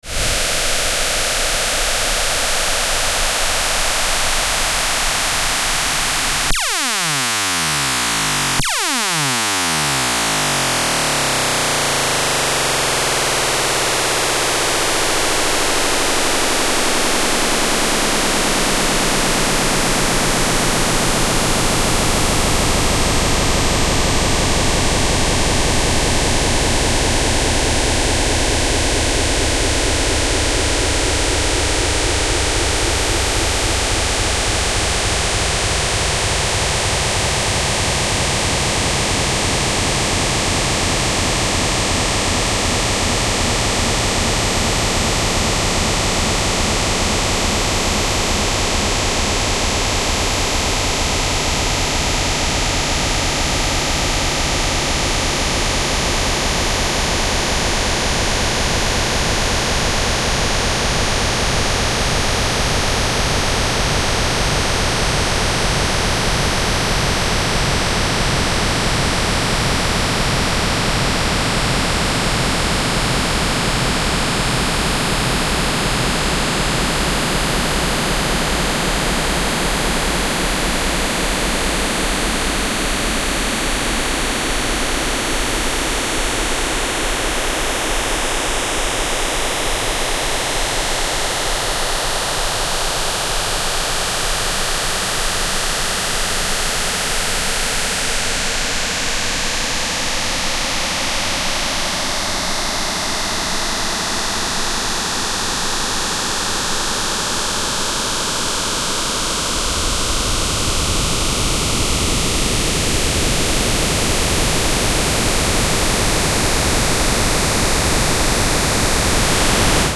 file) 4.58 MB Alter Mann aus der Türkei als Audiosignal 1